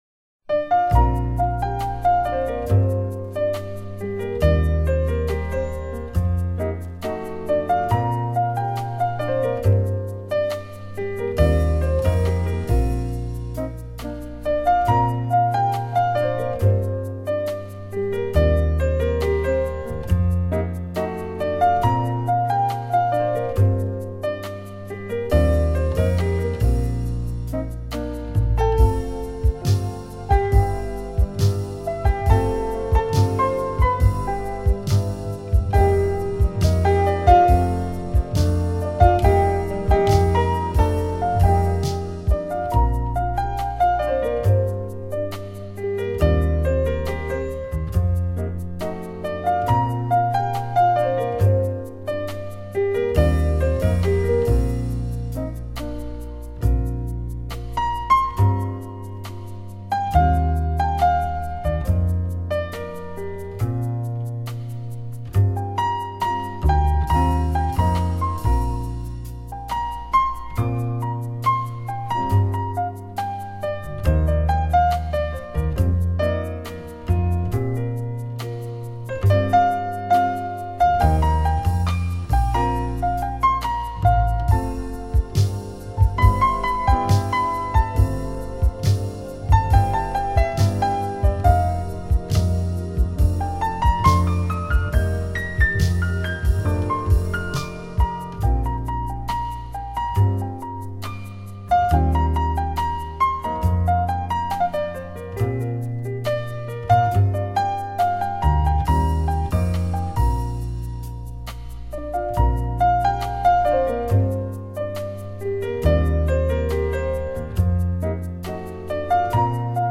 跟随唱片的走动，您会进入梦幻、沉思、幸福的奇妙境界，整张唱片听完会有神清气爽、豁然开朗之感。